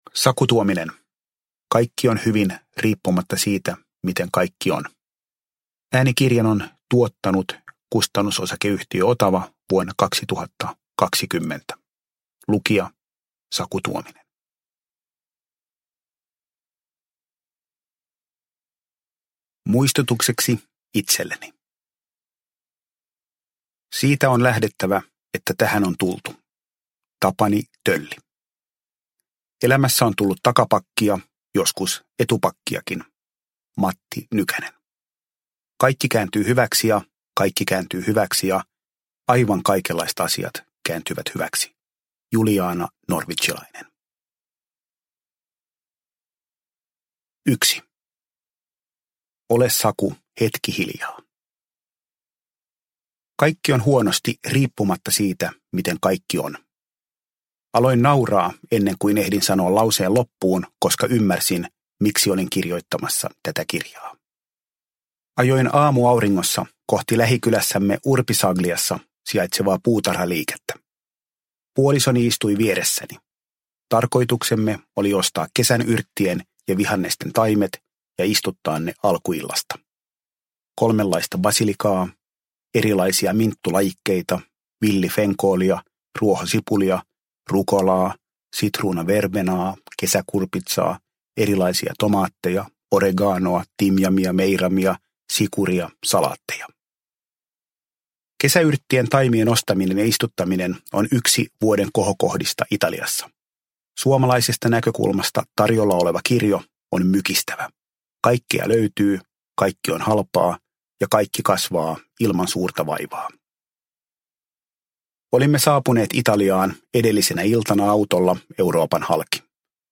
Kaikki on hyvin – Ljudbok – Laddas ner